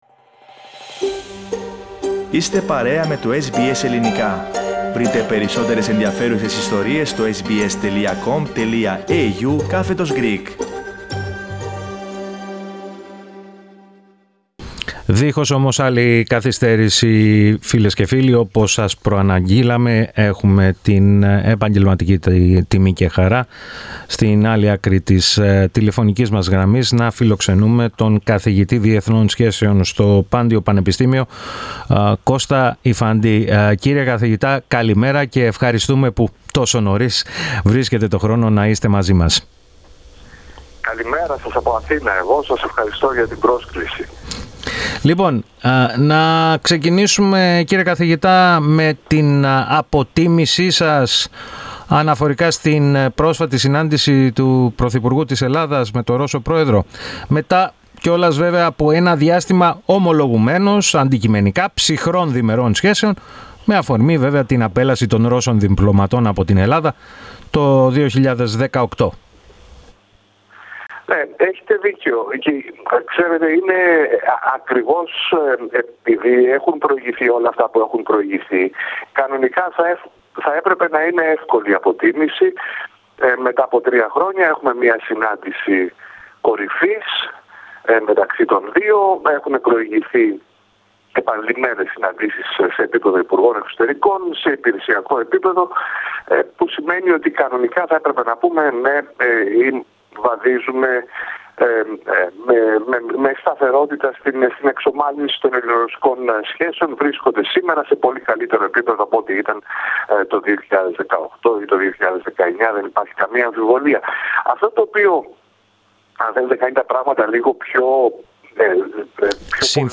Ακούστε ολόκληρη τη συνέντευξη, πατώντας το σύμβολο στο μέσο της κεντρικής φωτογραφίας.